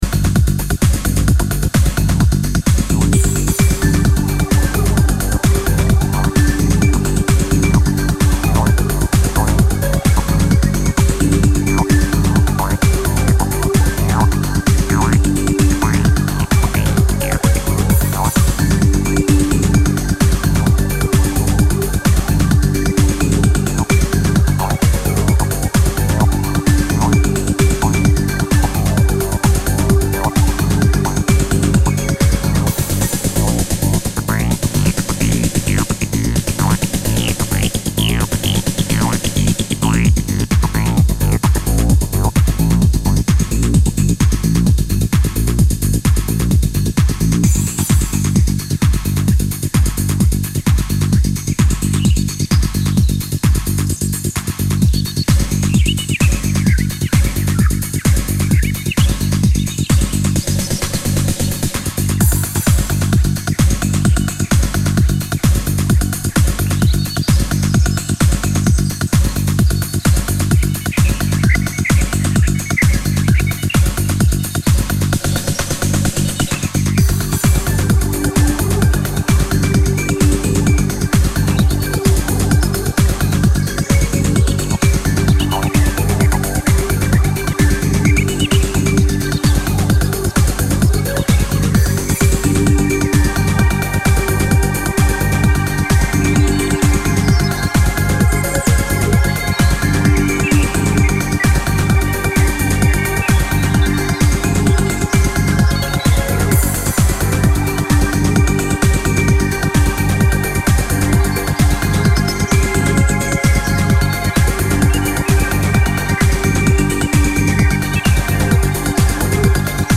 2x12inch Vinyl